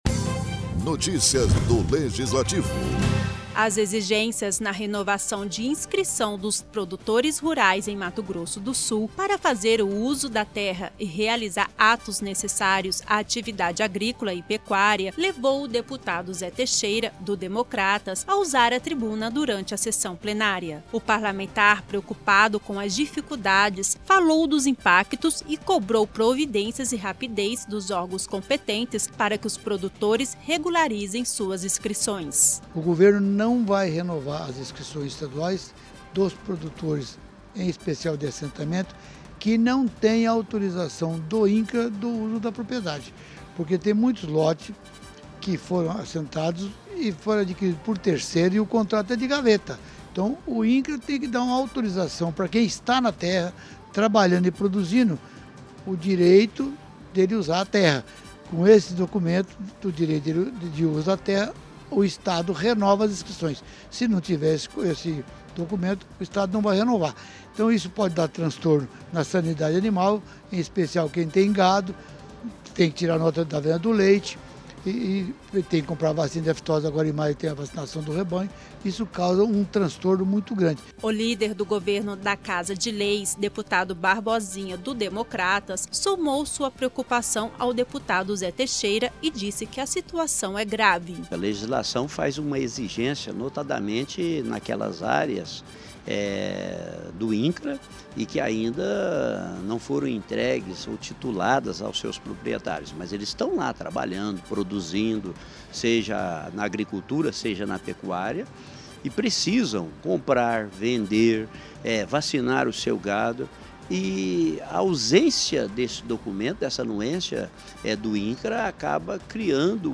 As exigências na renovação de inscrição do pequeno produtor rural em Mato Grosso do Sul  levou o deputado  Zé Teixeira,  do Democratas,  a usar a  tribuna durante a sessão plenária desta quinta-feira. O deputado afirmou que os produtores estão sofrendo dificuldades para regularizar suas inscrições e, por conta disso, são impedidos de realizar atos necessários à atividade agrícola e pecuária.